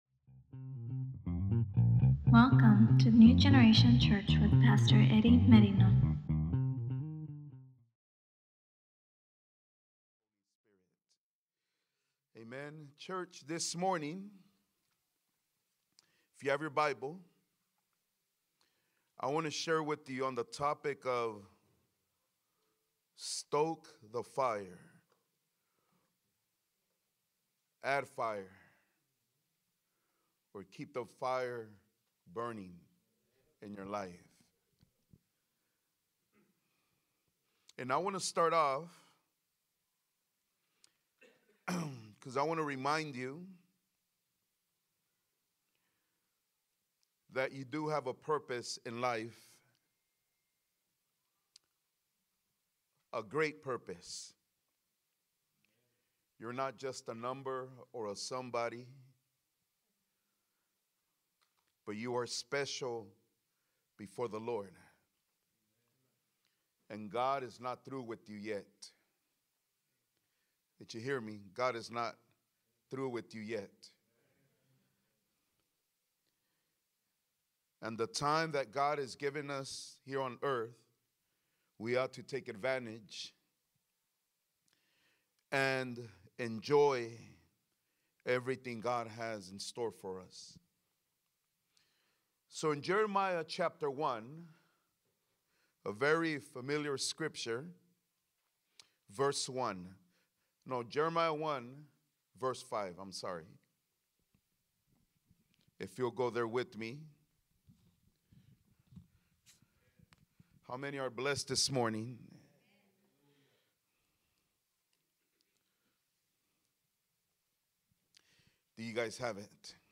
ECNG English Sunday Sermons